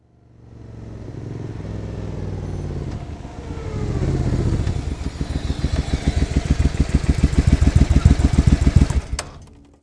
Index of /90_sSampleCDs/AKAI S6000 CD-ROM - Volume 6/Transportation/MOTORCYCLE
1200-BACK.WAV